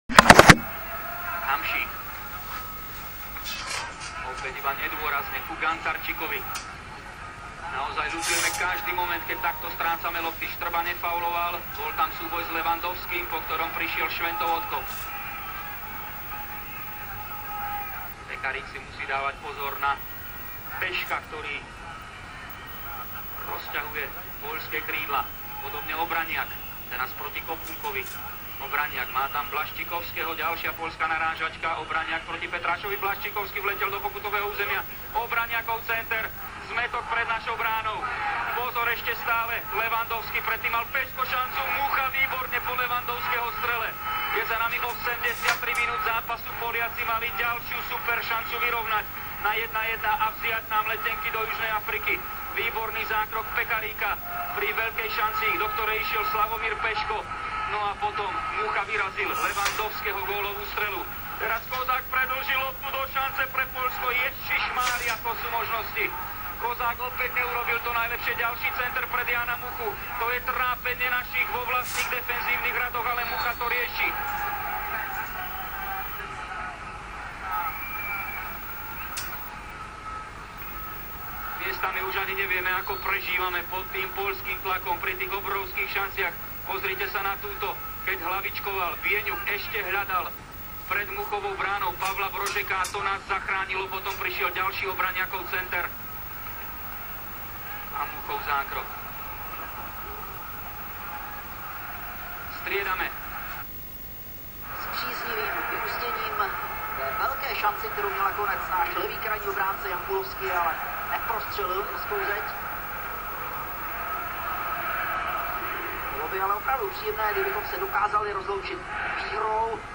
V přiloženém souboru si můžete poslechnout konec utkání, tak, jak jej včera slyšeli diváci slovenské televize. Záznam začíná 11 minut před koncem utkání, v čase 11.45 (na nahrávce) vypukla óóóóóbrovská radost Slováků, která pokračuje několik dalších minut.